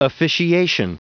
Prononciation du mot officiation en anglais (fichier audio)
Vous êtes ici : Cours d'anglais > Outils | Audio/Vidéo > Lire un mot à haute voix > Lire le mot officiation